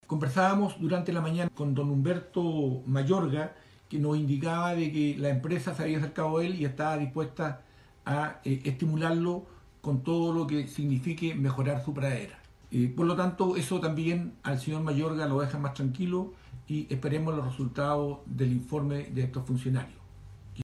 En este sentido, la autoridad de la provincia de Palena manifestó que la empresa se hará cargo de resarcir los daños producidos en el campo donde ocurrió el derrame.